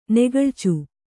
♪ negaḷcu